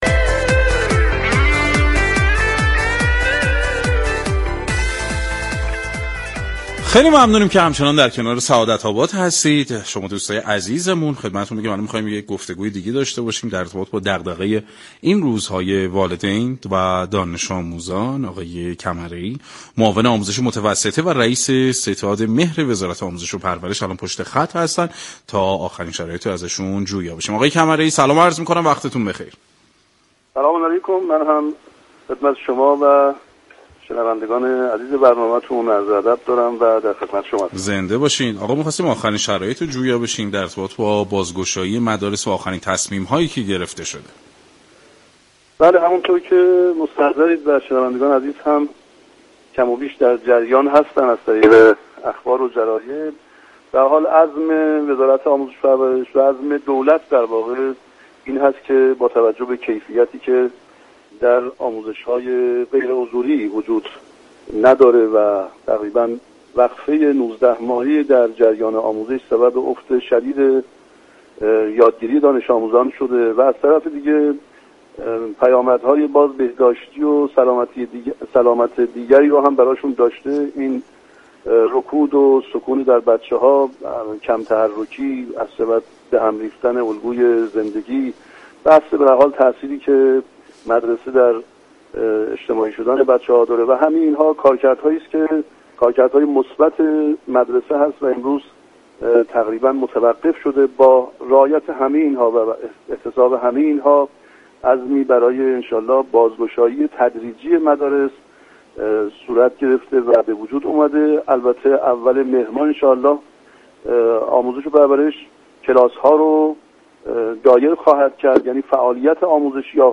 علیرضا كمره ای معاون آموزش متوسطه و رئیس ستاد مهر وزارت آموزش و پرورش در گفتگو با برنامه سعادت آباد